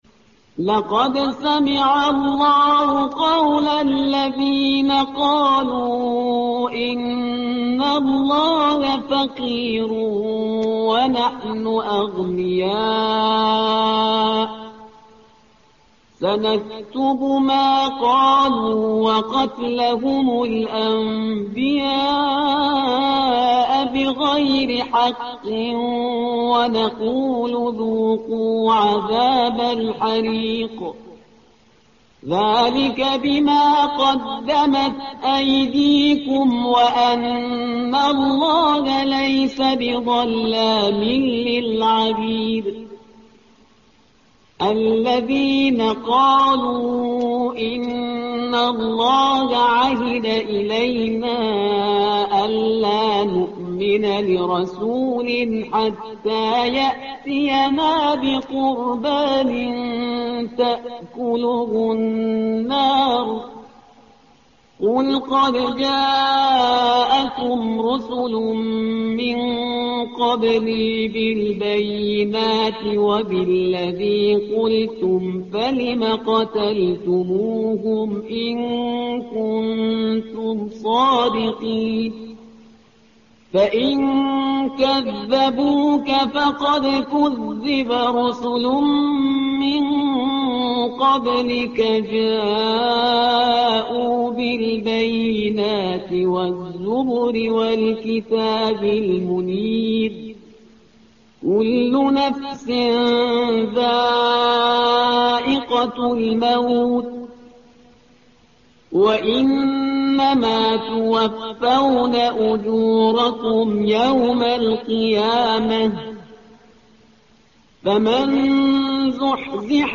الصفحة رقم 74 / القارئ